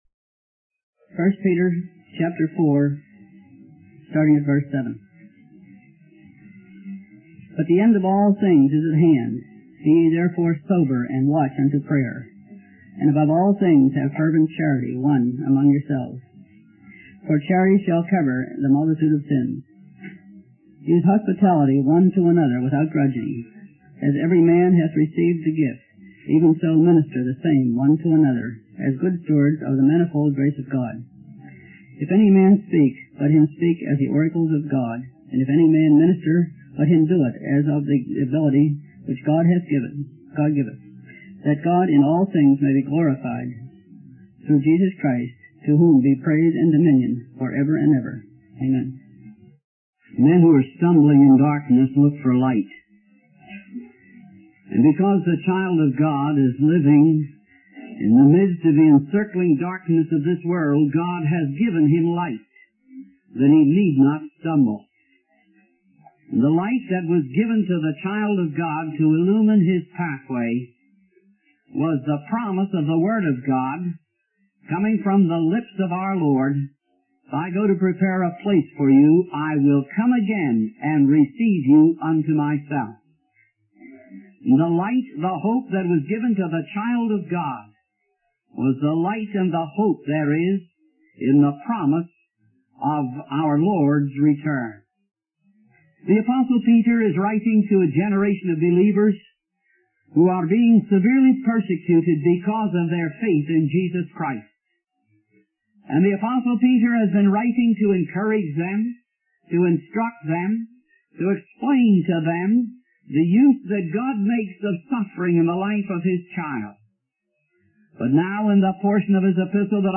In this sermon on 1 Peter, Chapter 4, starting at verse 7, the preacher emphasizes the imminent return of Jesus Christ and its implications for believers. He highlights three characteristics that should be evident in the lives of believers who have the hope of Christ's coming. These characteristics are sobriety, watchfulness in prayer, and fervent love for one another.